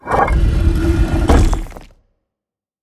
sounds / doors / stone / close.ogg